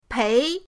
pei2.mp3